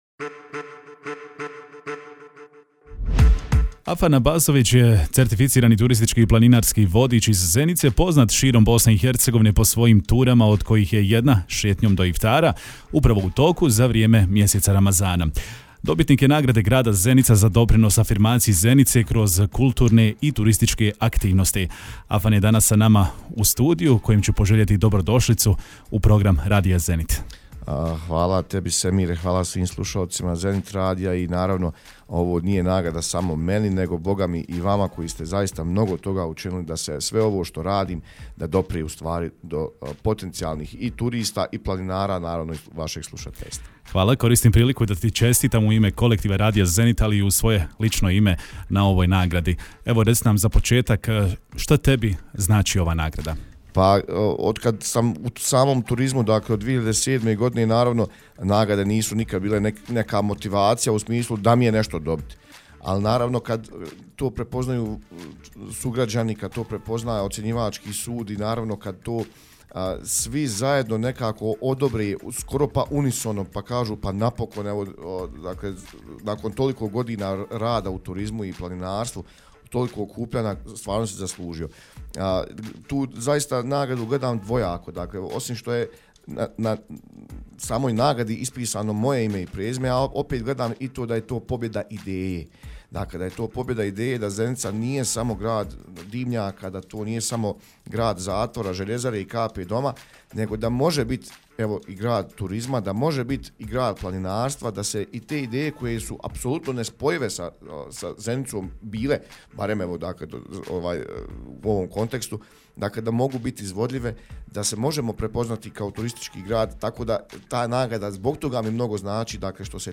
bio je gost u programu Radija Zenit